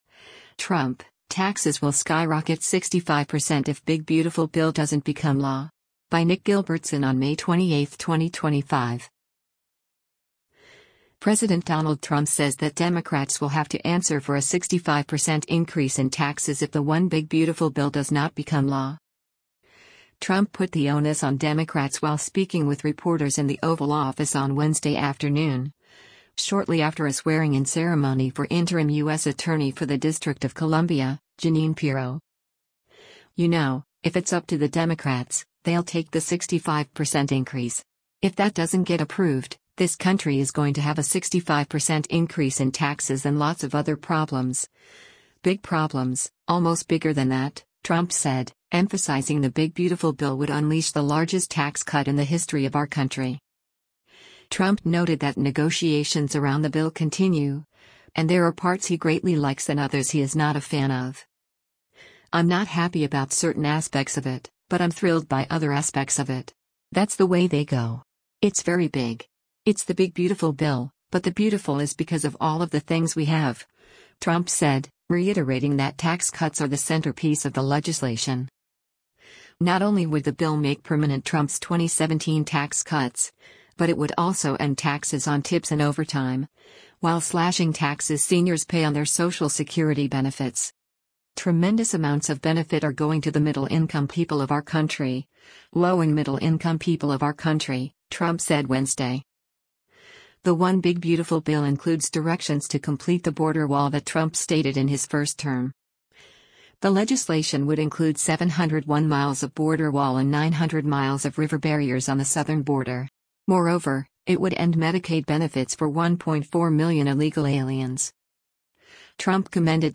Trump put the onus on Democrats while speaking with reporters in the Oval Office on Wednesday afternoon, shortly after a swearing-in ceremony for interim U.S. Attorney for the District of Columbia, Jeanine Pirro.